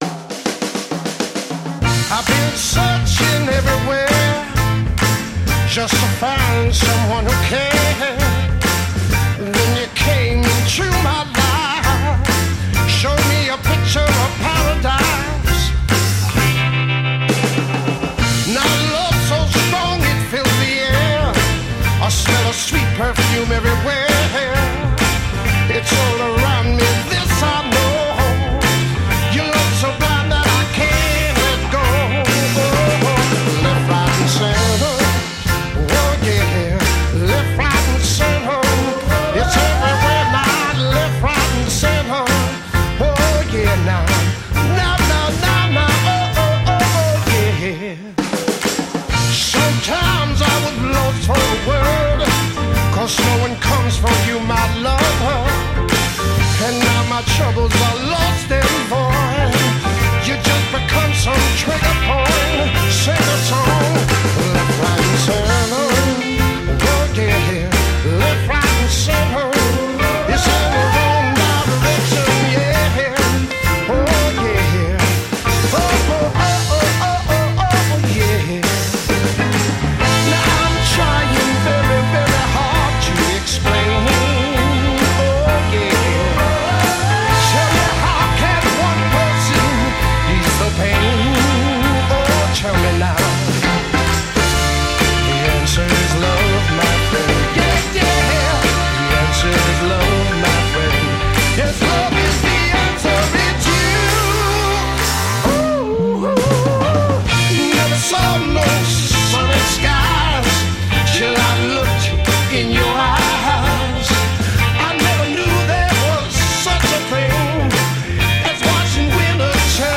strepitosa cover